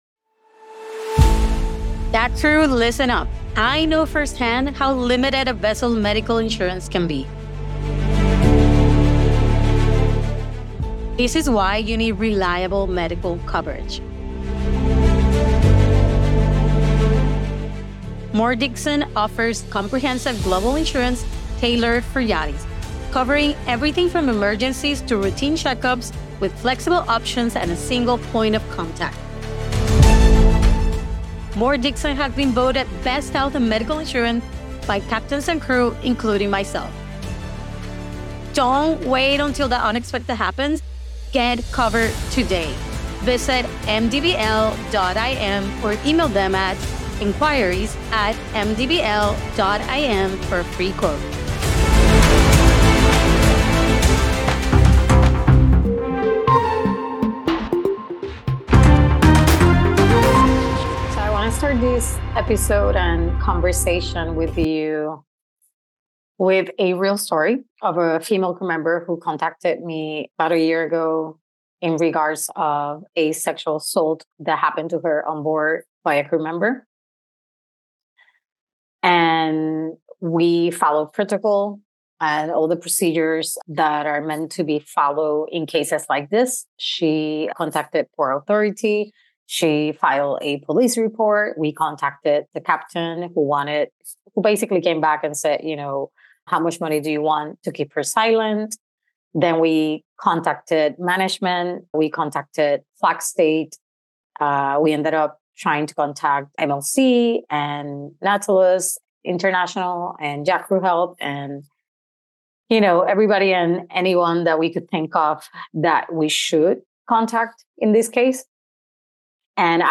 UNCENSORED brings together maritime experts, lawyers, captains, mental health professionals, and crew to reveal the human stories behind yachting.